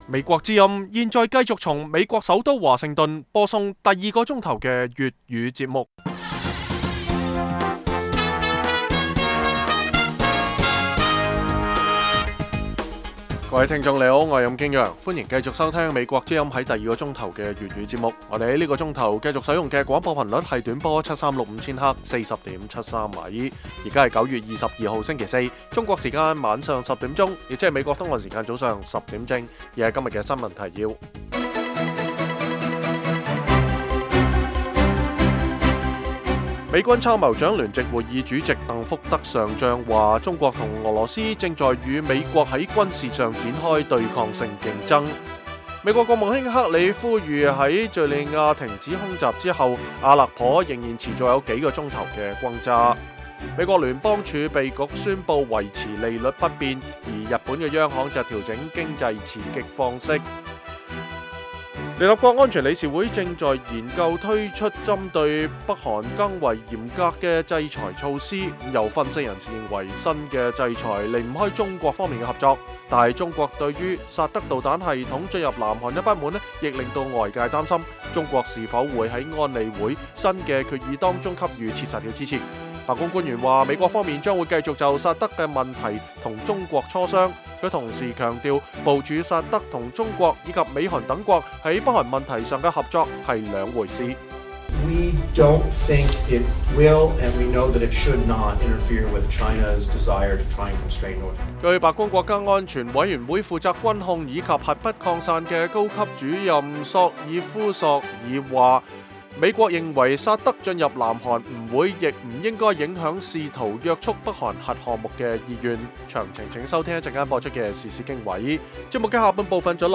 北京時間每晚10－11點 (1400-1500 UTC)粵語廣播節目。內容包括國際新聞、時事經緯、英語教學和社論。